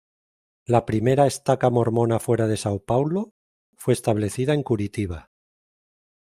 Pronounced as (IPA) /esˈtaka/